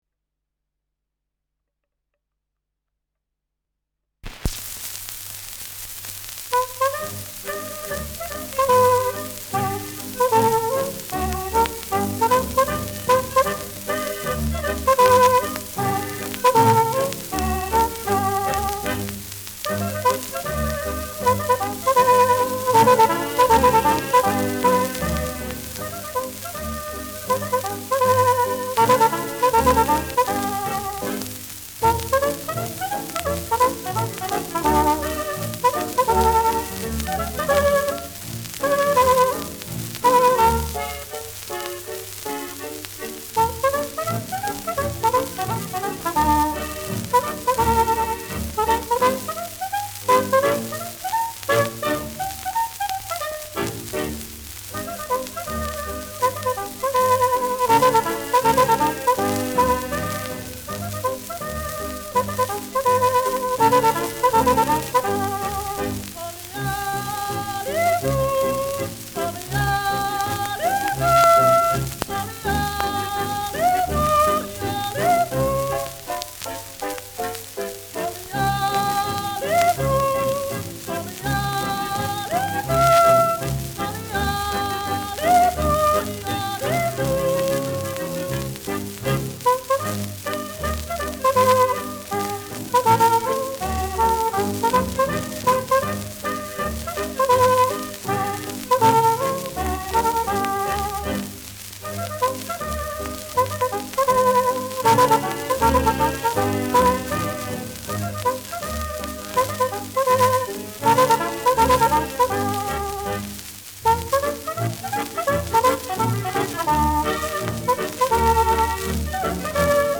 Schellackplatte
Ländlerkapelle* FVS-00018